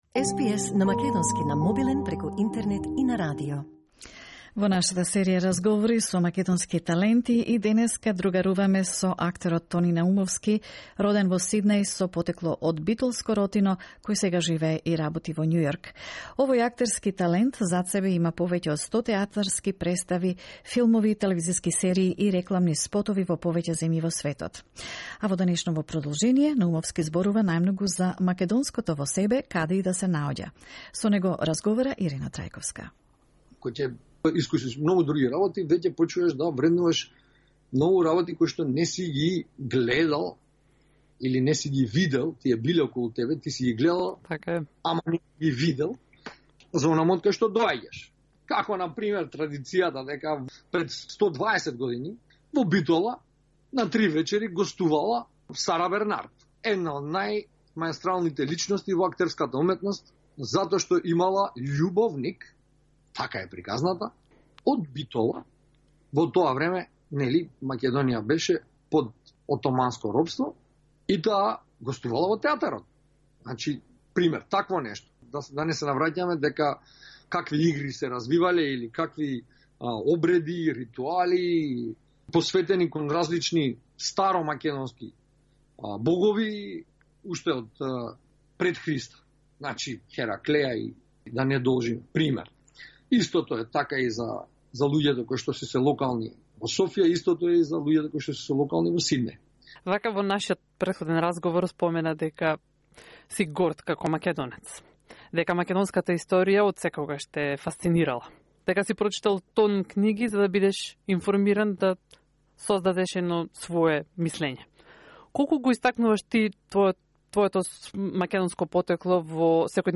In the third part of the interview with the world-established Macedonian actor, he talks about his love of history, the Macedonian music and traditions he takes with him and presents it around the world.